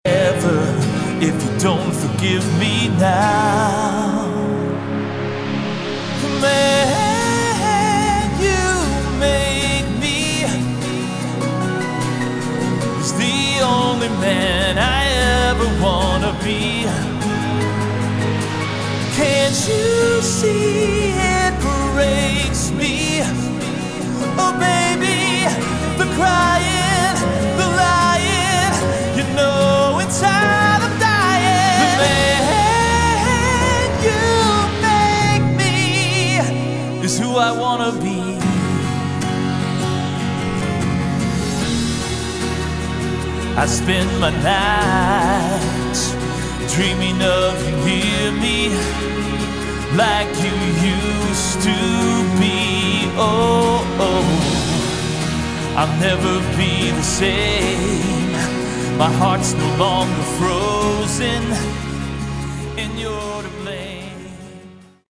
Category: AOR / Melodic Rock